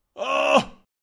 electroshock
描述：sonido de electroshock
标签： electroshock scream pain
声道立体声